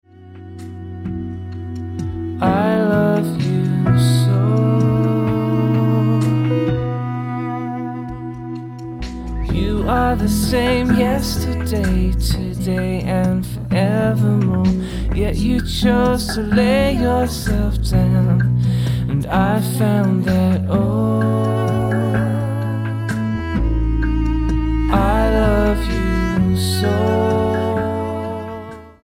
Pop Album
Style: Pop